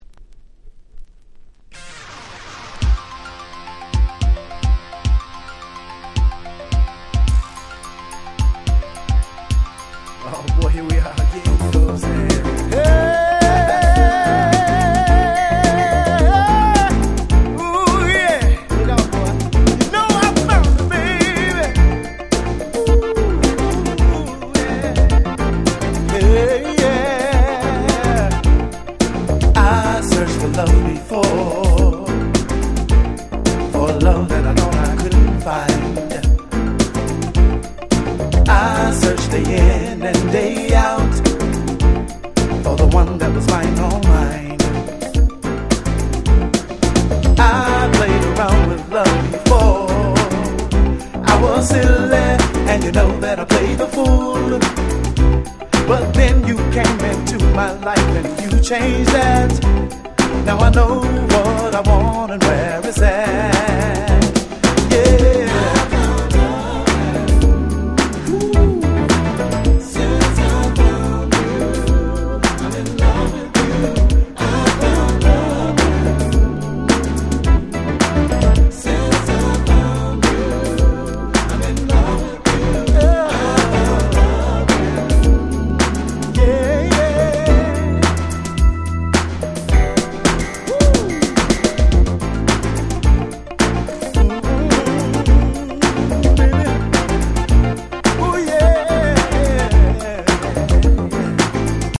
95' Very Nice R&B / New Jack Swing !!
90's NJS ハネ系